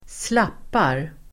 Uttal: [²sl'ap:ar]